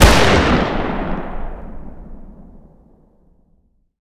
fire-dist-44mag-pistol-ext-03.ogg